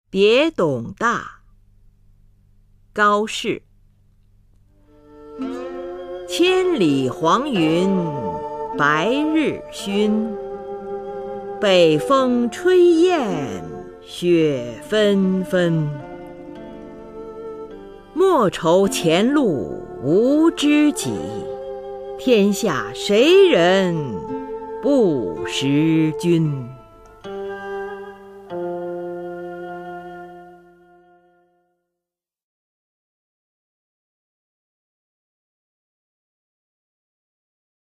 [隋唐诗词诵读]高适-别董大 古诗文诵读